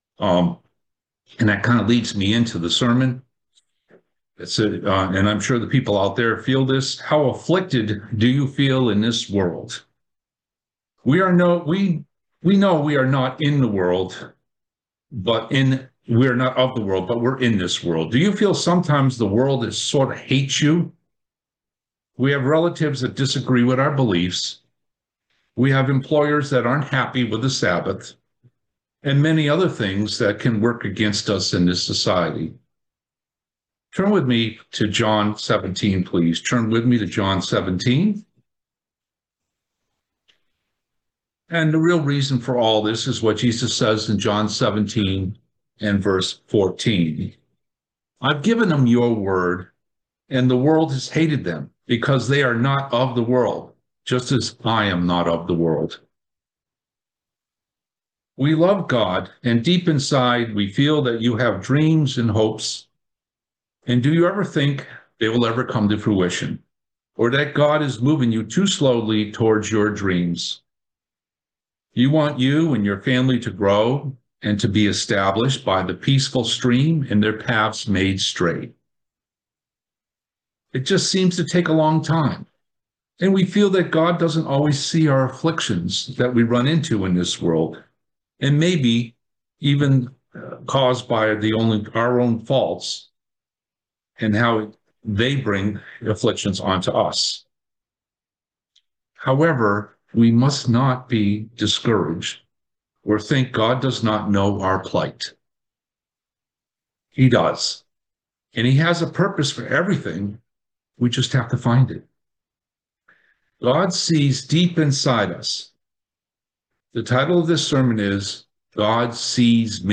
Does he know our plight, and see our afflictions? Join us for this very interesting sermon about how God sees us.